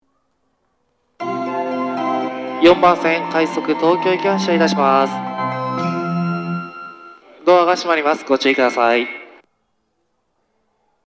発車メロディー
0.1コーラス（フルコーラス）です。